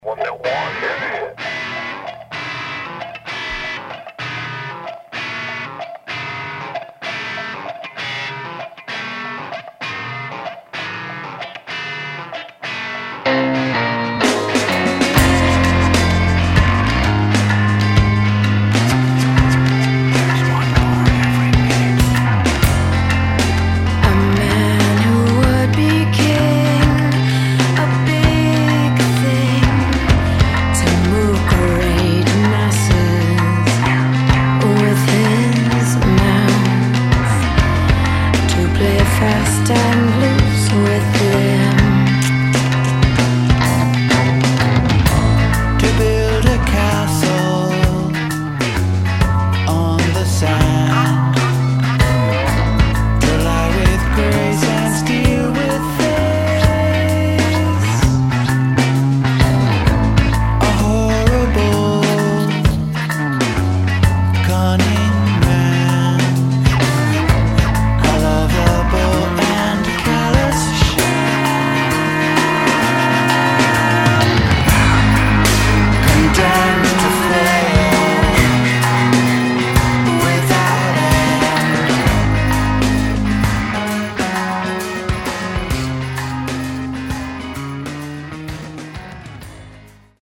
Bizzarri sperimentalisti?